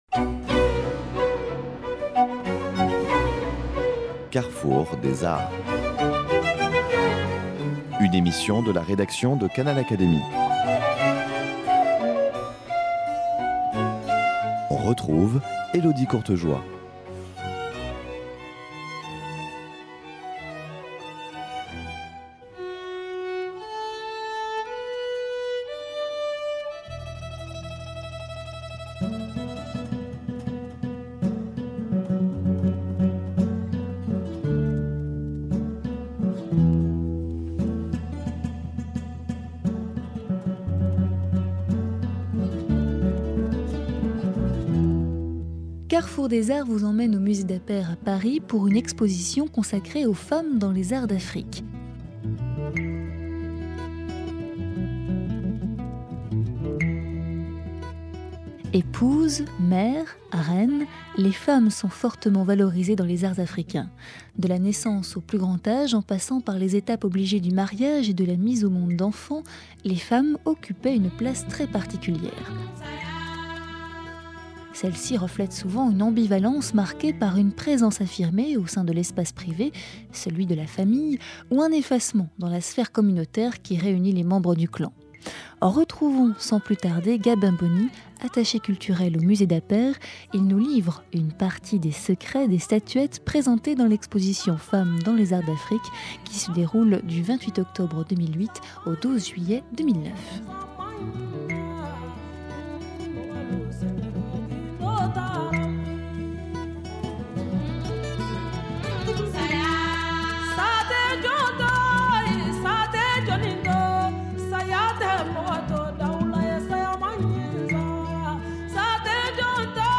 Parcours sonore de l’exposition